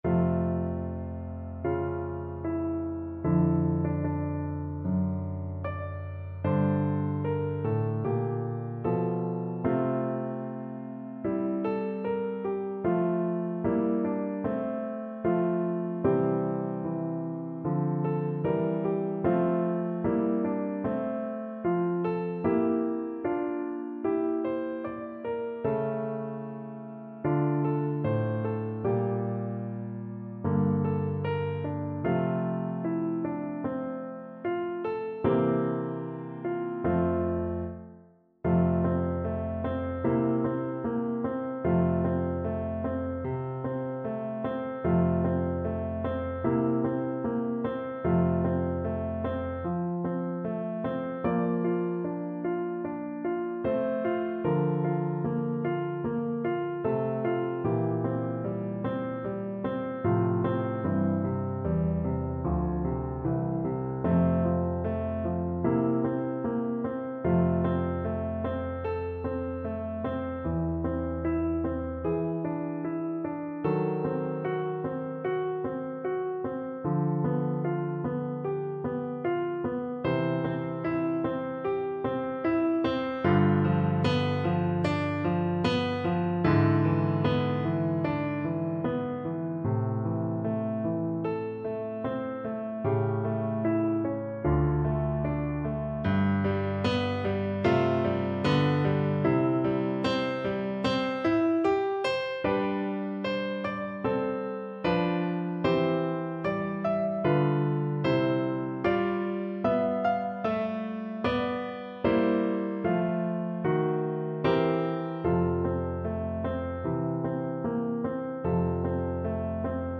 Free Sheet music for Choir (SA)
F major (Sounding Pitch) (View more F major Music for Choir )
4/4 (View more 4/4 Music)
Poco lento =100
Classical (View more Classical Choir Music)